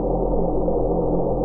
SPACECRAFT_Hover_04_loop_mono.wav